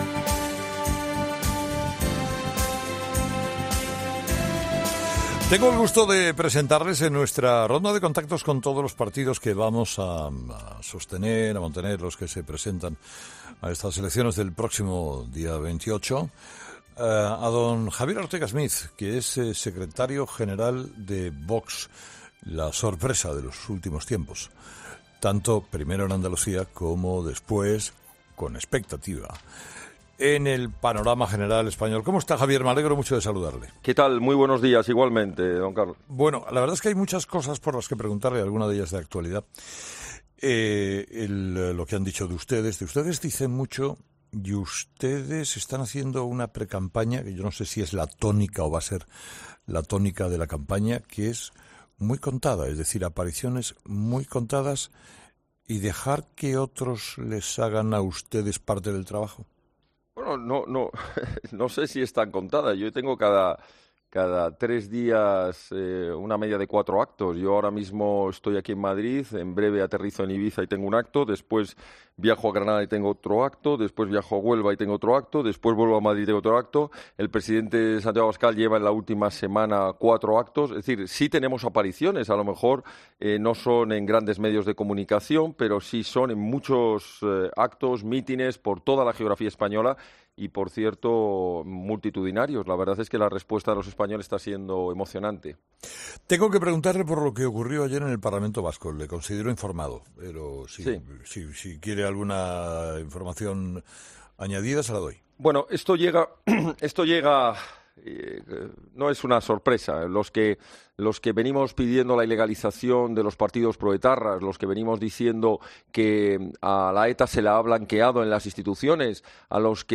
Entrevista completa de Carlos Herrera a Ortega Smith (Vox)